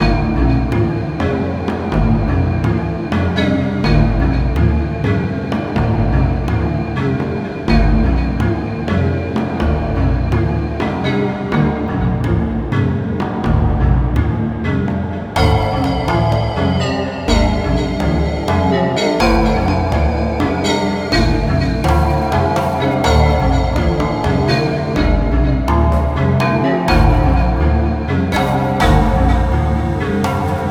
Instrument: Drums
Mysterious, Serious, Strange